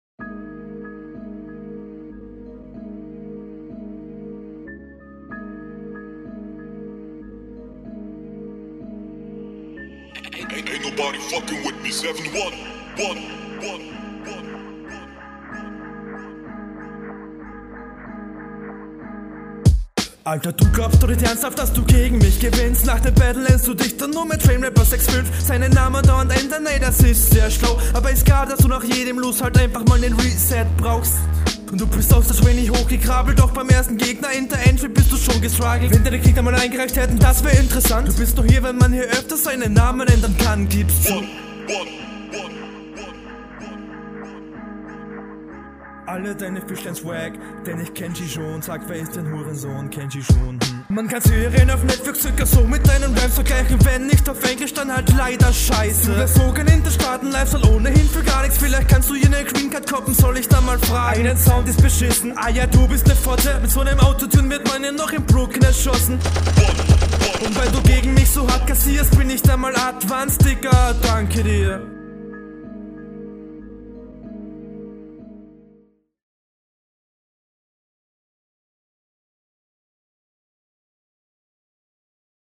find auch hier deine höhen etwas stechend. flow ganz cool aber wie runde 1 könntest …
Düster.
Der Doubletime ist unsauber .